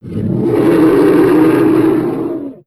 c_trex00_atk1.wav